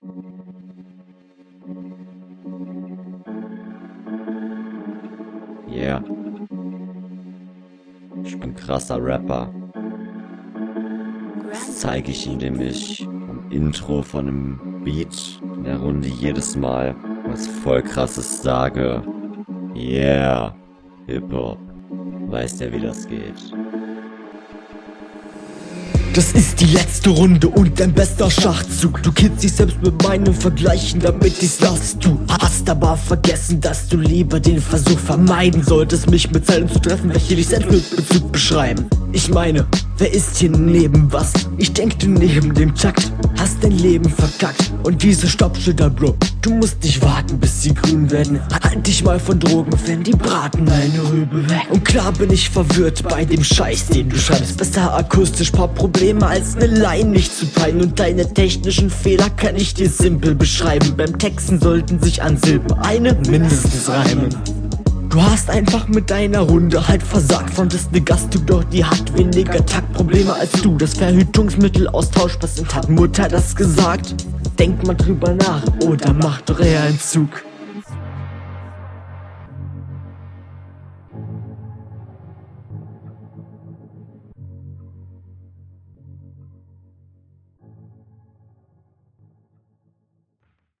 intro sehr funny mit dem filter. flow schonmal viel schöner. fand dich in den anderen …